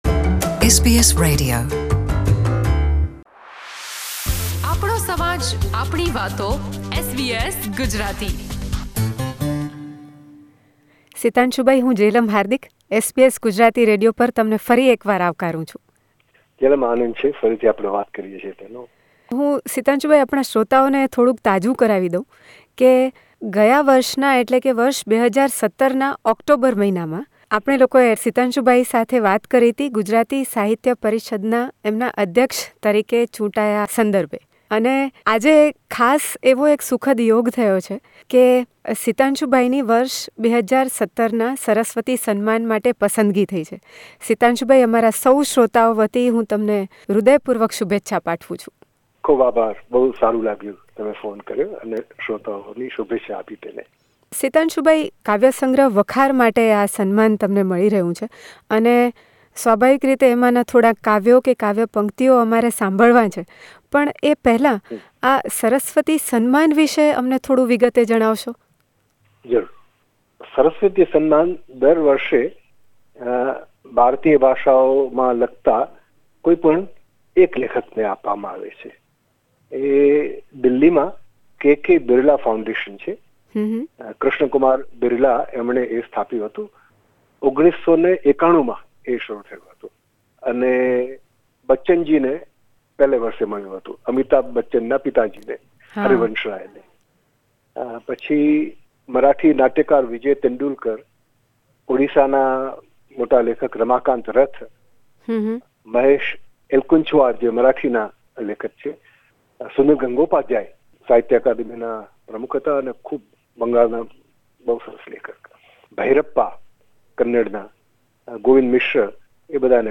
A scholarly Gujarati poet, play right and critic, Padma Shri Sitanshu Yashaschandra has been selected for this highly reputed annual award for his outstanding literary contribution in Gujarati. He quotes few very expressive lines from his rewarded collection of poetry 'Vakhar'.